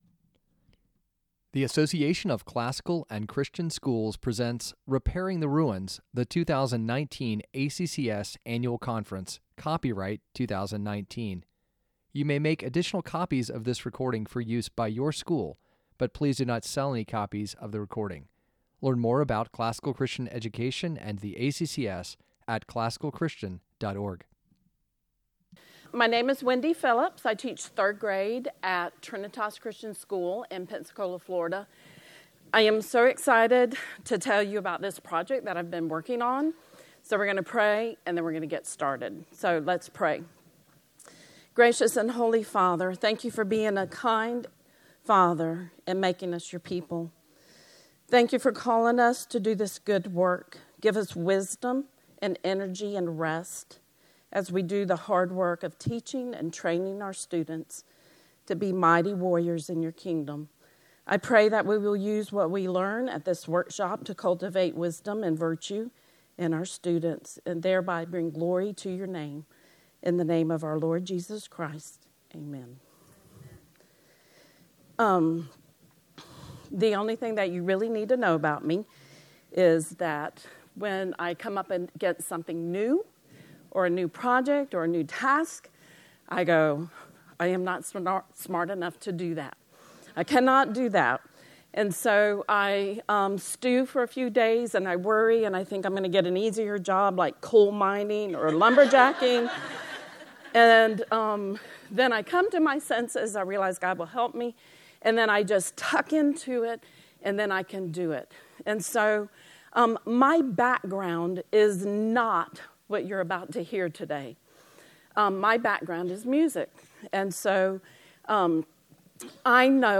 2019 Workshop Talk | 01:01:41 | K-6, History